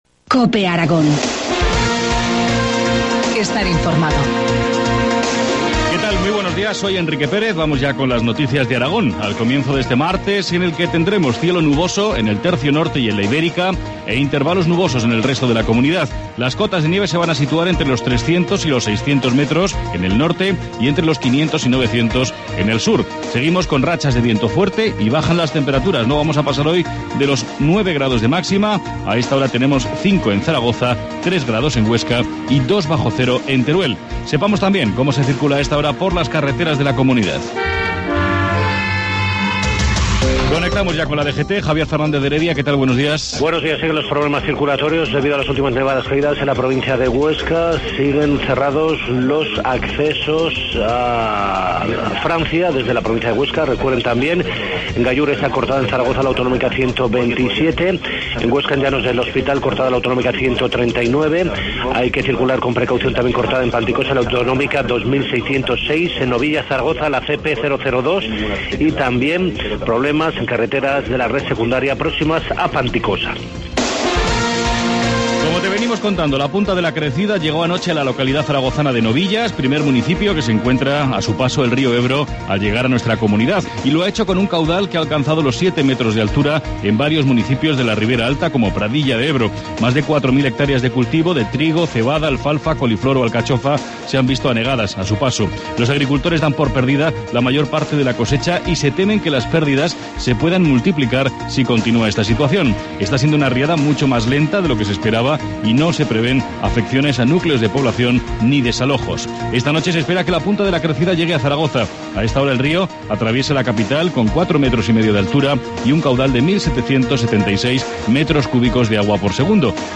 Informativo matinal, martes 22 de enero, 7.25 horas